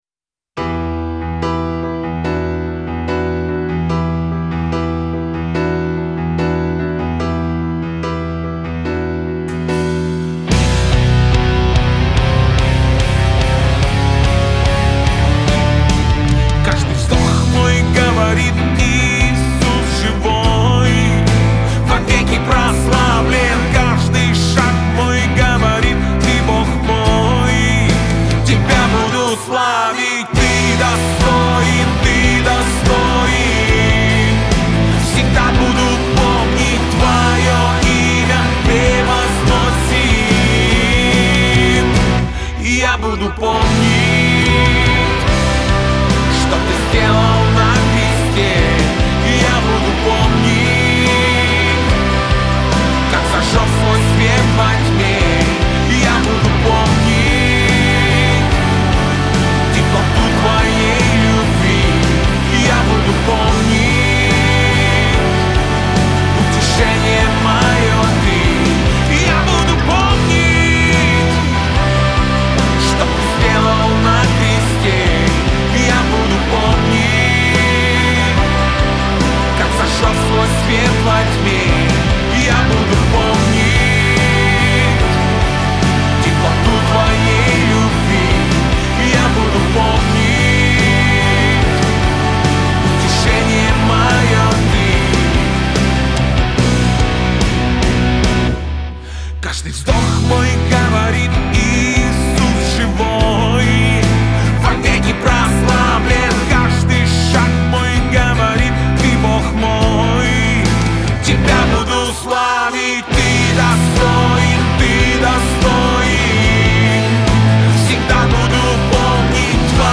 2084 просмотра 2189 прослушиваний 219 скачиваний BPM: 145